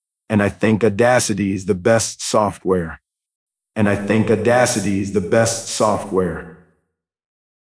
Speaker effect
It does sound like a large concert venue , (but it does not include microphone emulation: so too clean IMO) There’s Steve’s “Public Address” plugin, but that’s low-budget venue, like a small-club, (maybe too dirty) …